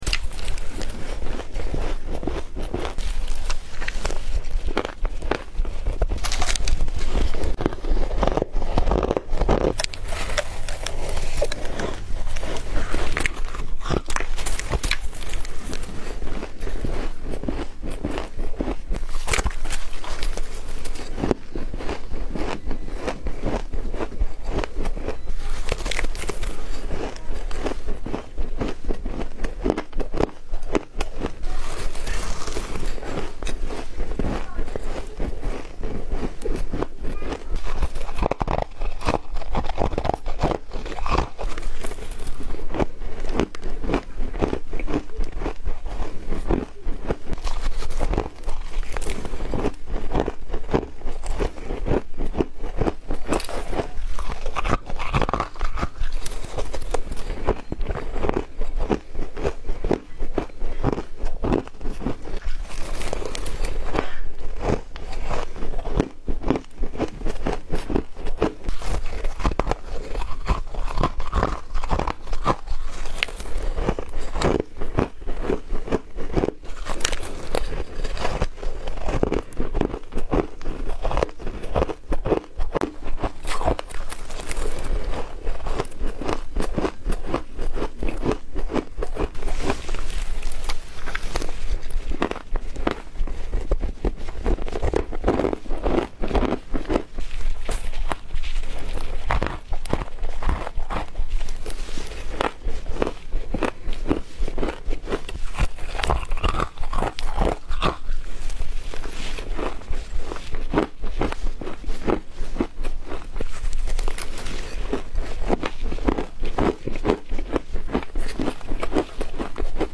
Mix textured freezer frost [full